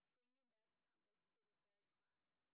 sp27_street_snr10.wav